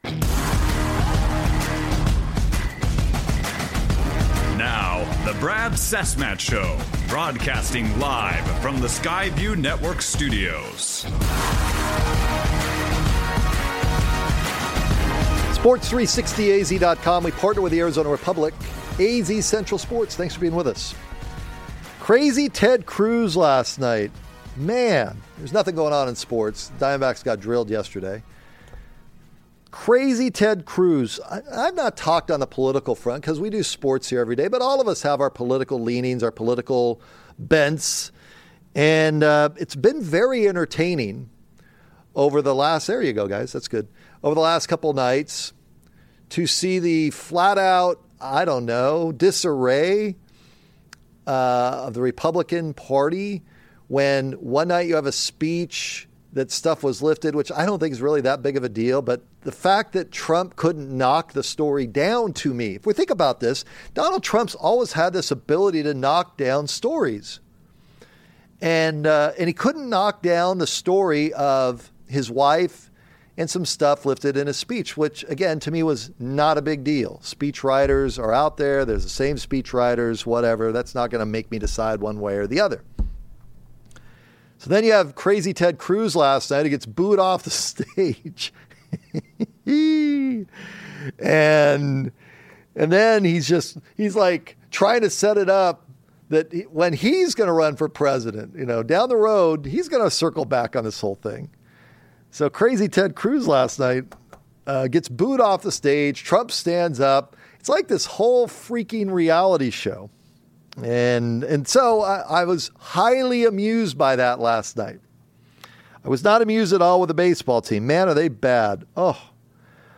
In-Studio